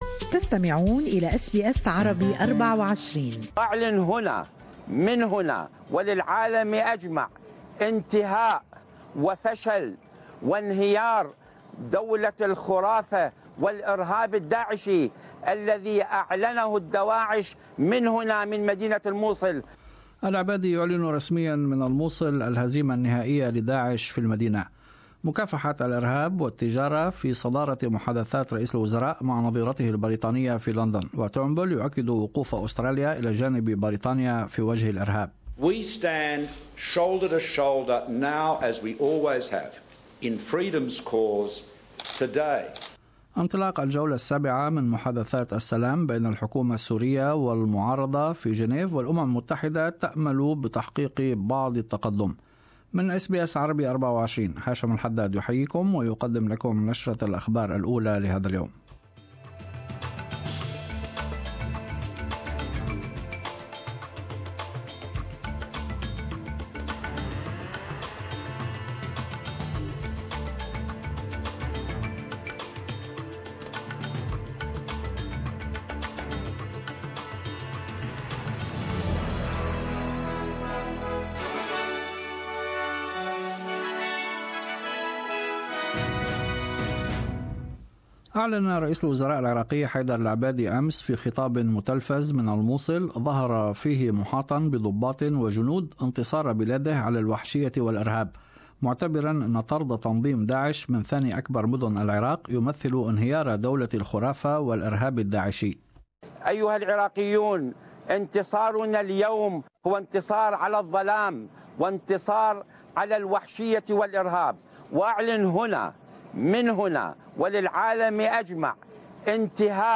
Morning news bulletin.